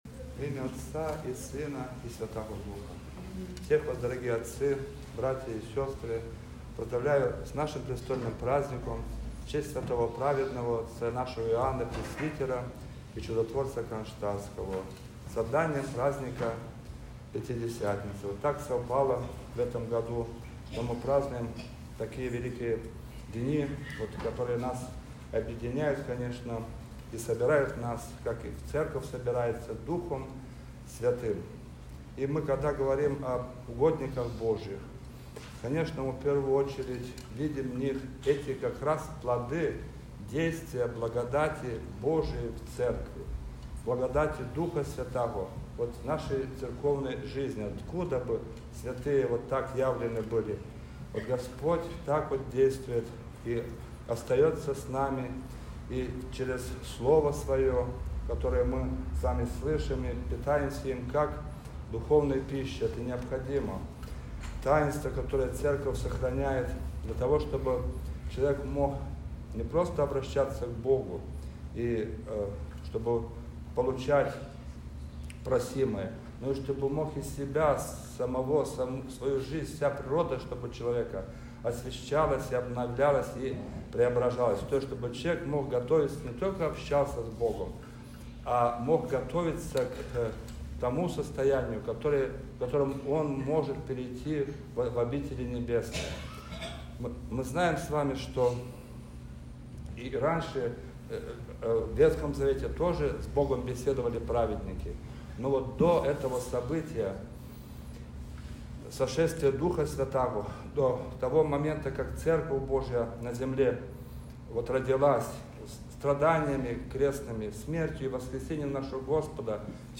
Божественная-литургия.-Престольный-праздник.mp3